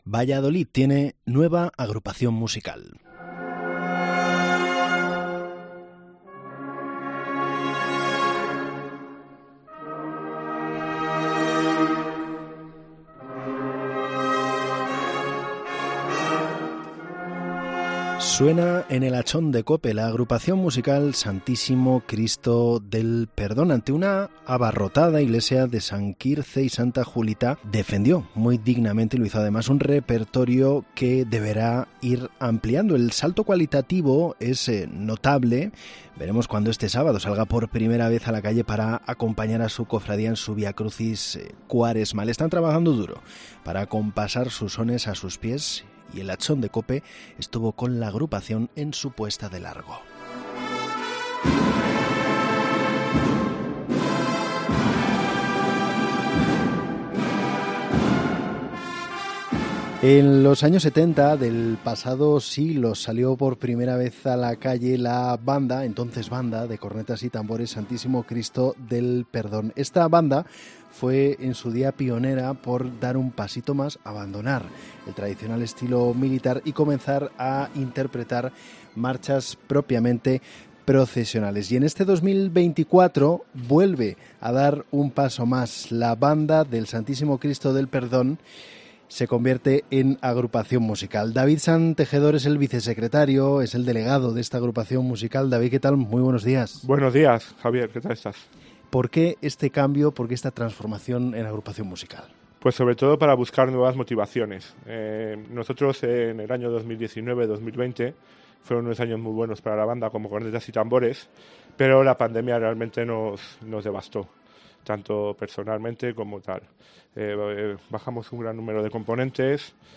Entrevista en El Hachón de COPE a la Agrupación Musical Santísimo Cristo del Perdón de Valladolid